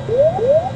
alert.ogg